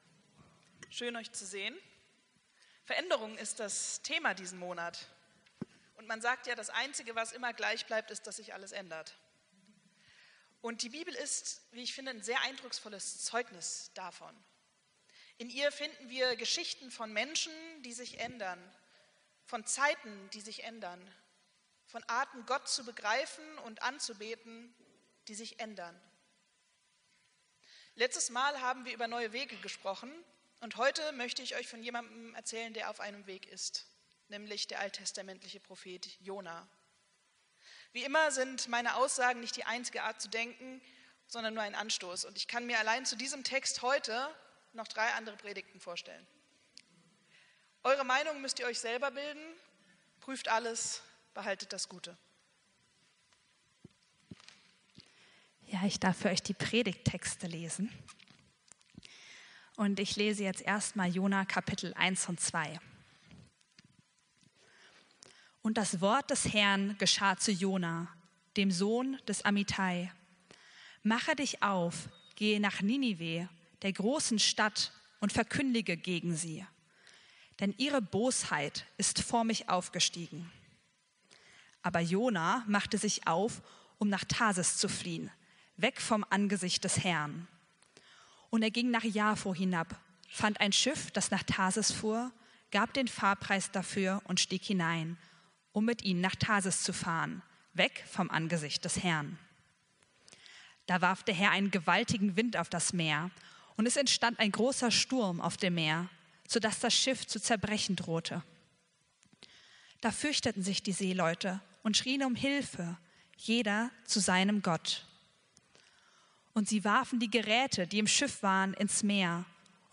Predigt vom 09.03.2025